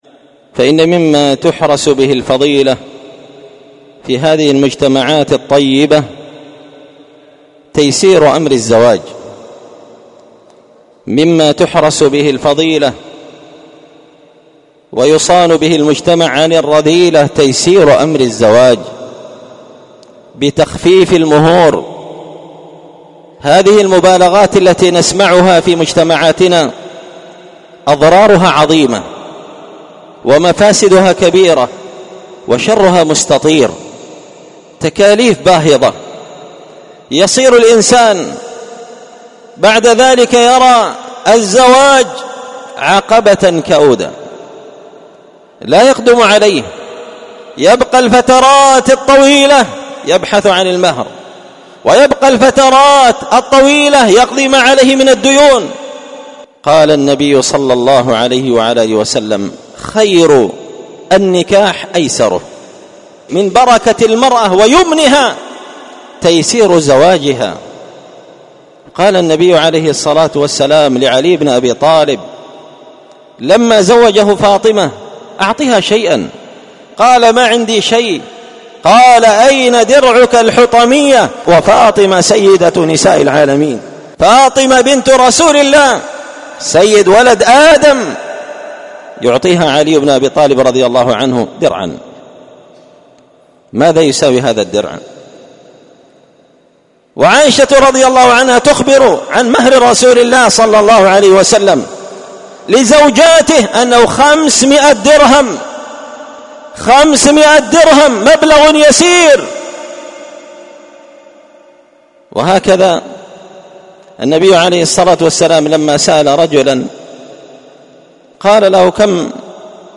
سلسلة مقتطفات من خطبة جمعة بعنوان حراسة الفضيلة وحماية المجتمع من الرذيلة ⏸المقتطف العاشر⏸السبب الثامن من الأسباب التي تحرس وتحمى بها الفضيلة تيسير أمر الزواج وتخفيف المهور
دار الحديث بمسجد الفرقان ـ قشن ـ المهرة ـ اليمن